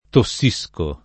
tossire
vai all'elenco alfabetico delle voci ingrandisci il carattere 100% rimpicciolisci il carattere stampa invia tramite posta elettronica codividi su Facebook tossire v.; tossisco [ to SS&S ko ], -sci (oggi meno com. tosso [ t 1SS o ])